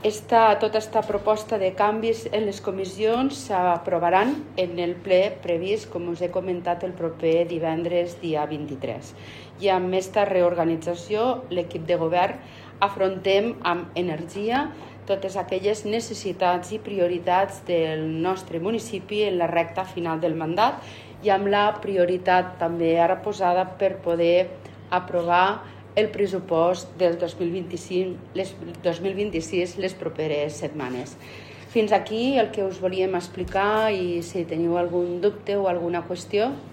Mar Lleixà, alcaldessa de Tortosa